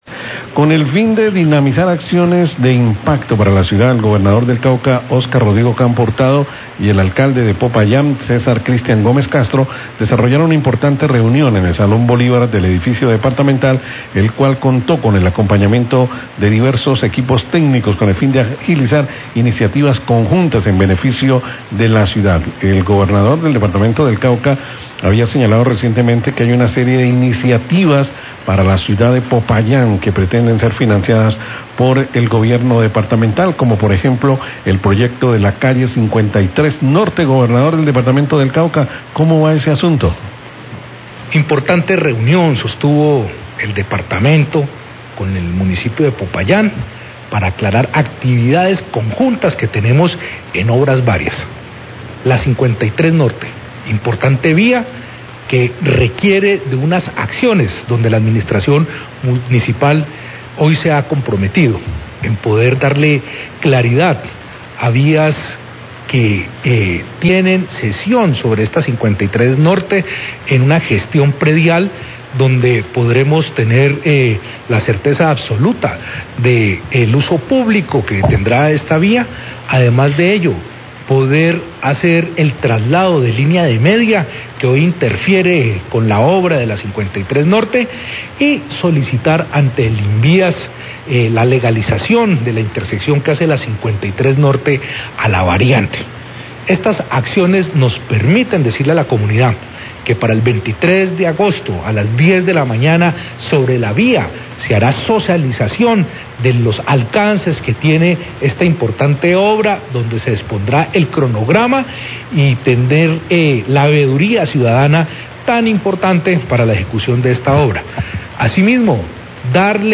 GOBERNADOR DEL CAUCA HABLA DE ASUNTOS PENDIENTES PARA AGILIZAR OBRAS CALLE 53N
Radio